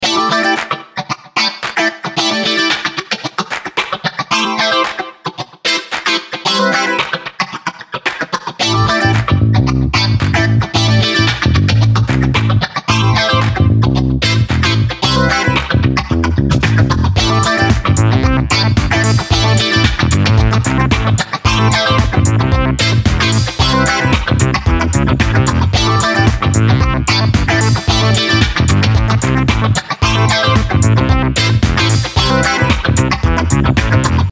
disco grooves